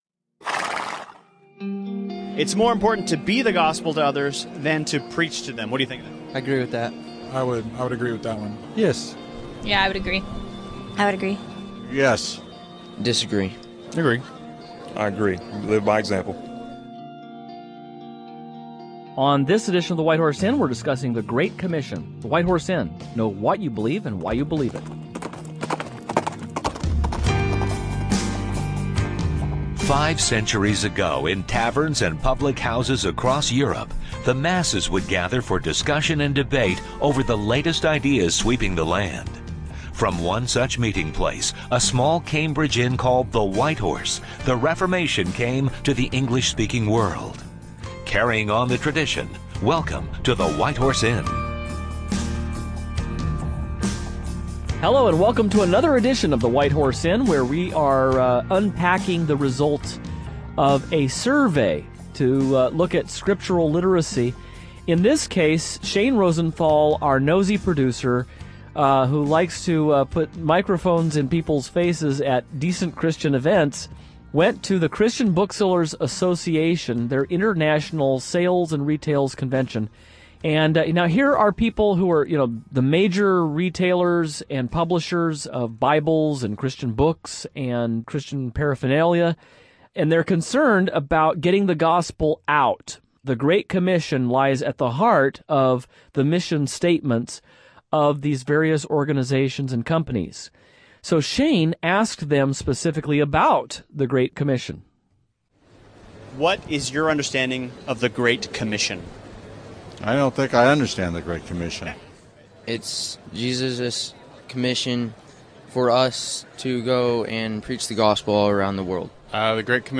On this broadcast the hosts continue to interact with the results from our recent survey of Christians…